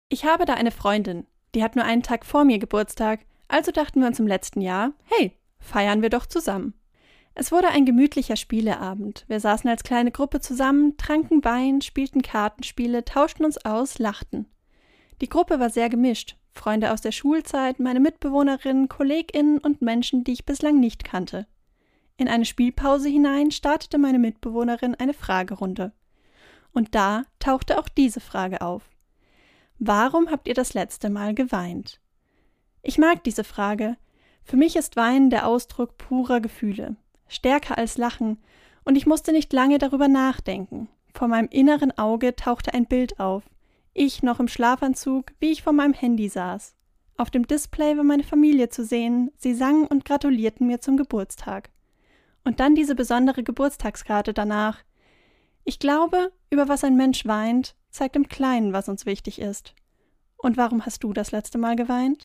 Februar 2025, Autorin und Sprecherin ist